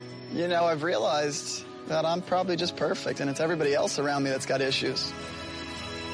The great American scream performace sound effects free download